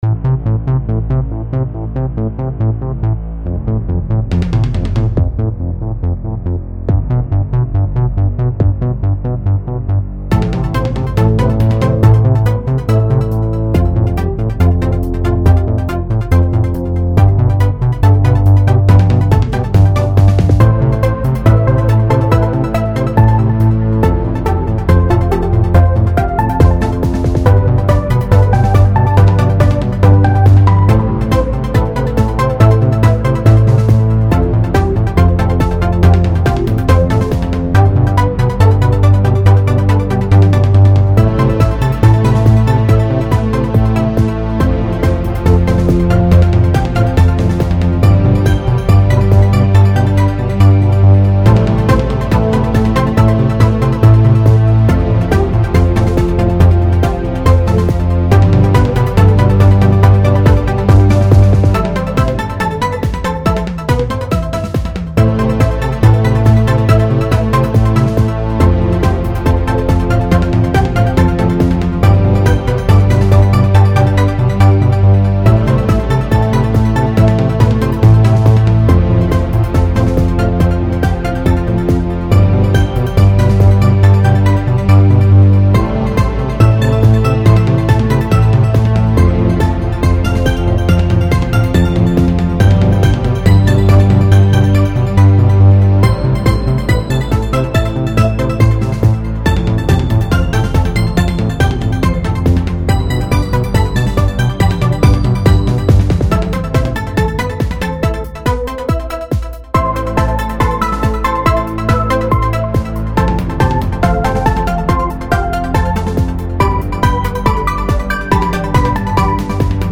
A techno song
in Fruity Loops. I decided I wanted a more upbeat sound for this one.